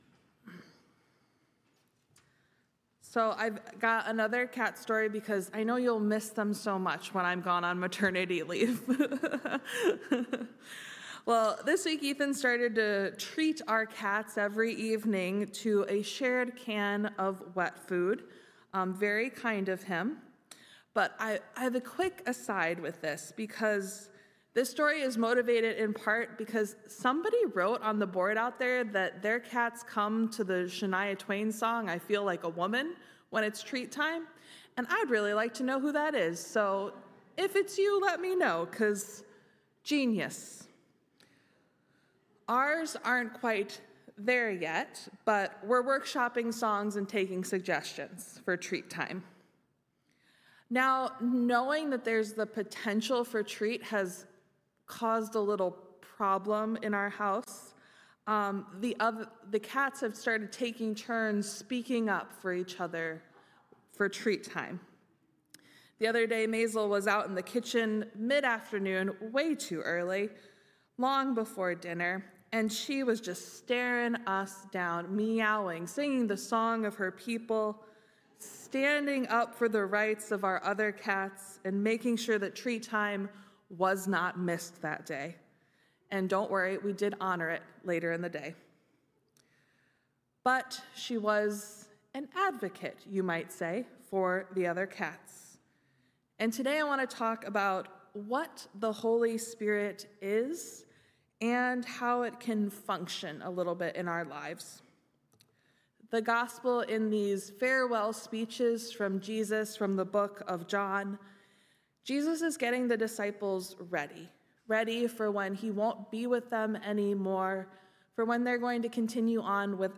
Sermons | Messiah Lutheran Church, Marquette